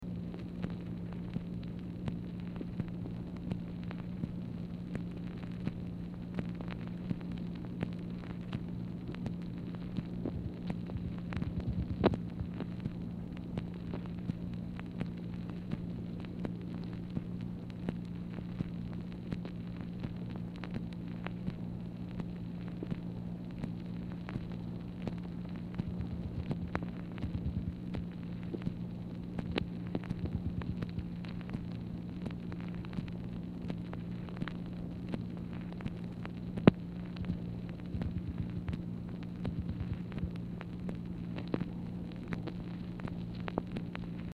Telephone conversation # 2901, sound recording, MACHINE NOISE, 4/8/1964, time unknown | Discover LBJ
Format Dictation belt
White House Telephone Recordings and Transcripts Speaker 2 MACHINE NOISE